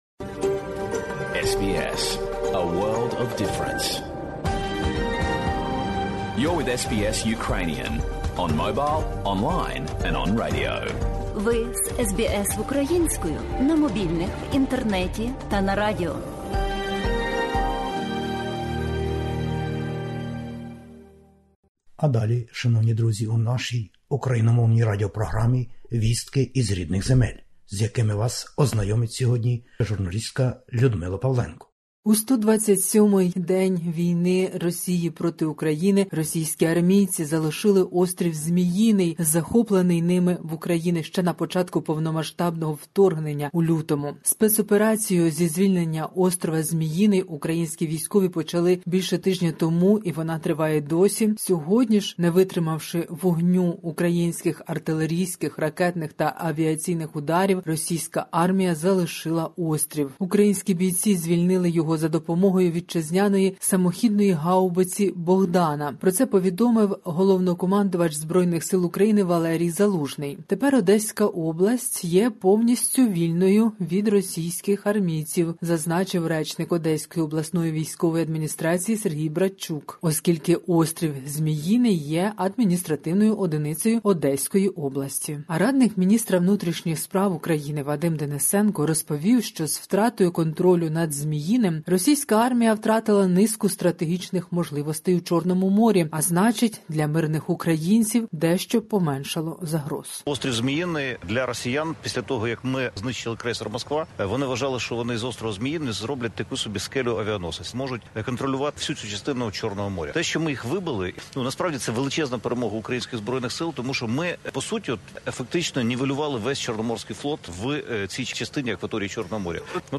Добірка новин із героїчної України та про Україну.